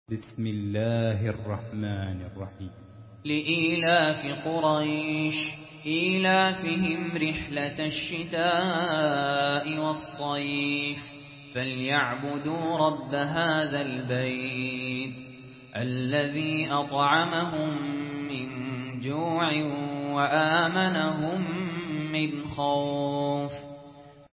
Surah Quraish MP3 by Sahl Yassin in Hafs An Asim narration.
Murattal